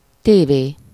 Ääntäminen
IPA: [te.le.vi.zjɔ̃]